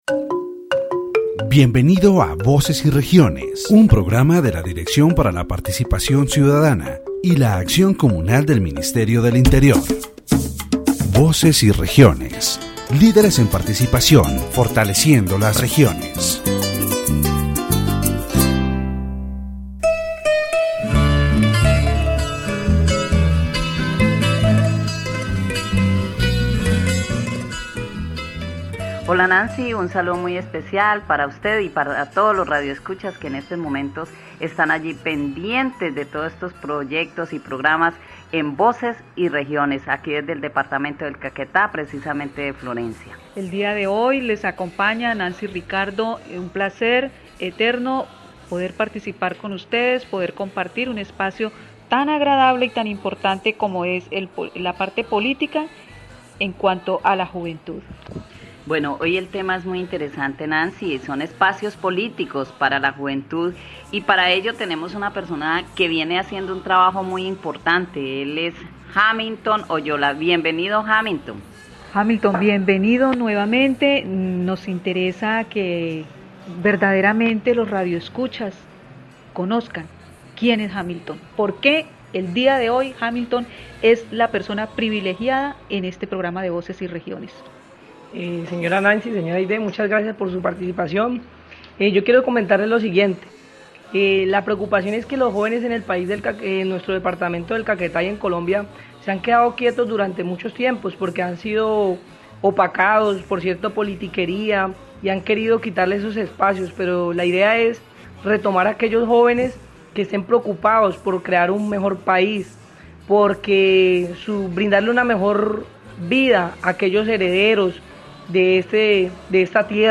The radio program "Voices and Regions" of the Directorate for Citizen Participation and Community Action of the Ministry of the Interior focuses on the political participation of youth in the department of Caquetá.